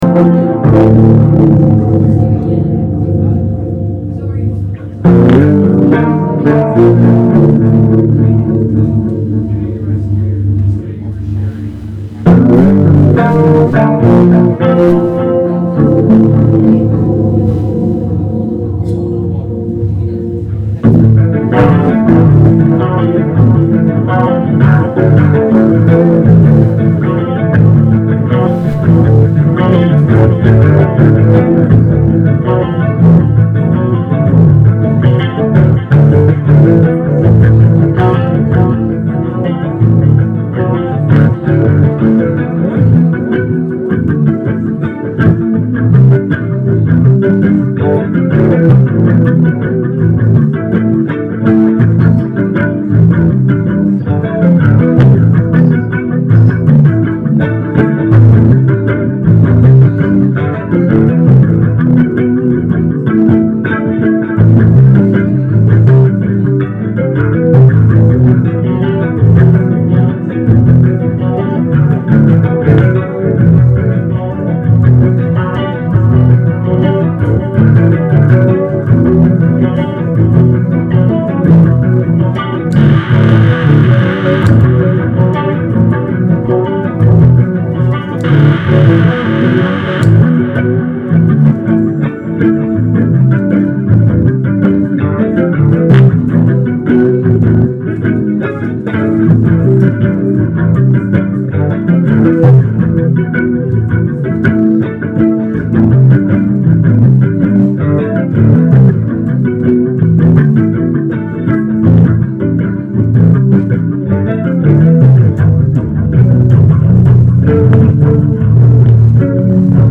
Live performance of band that plays in style of in...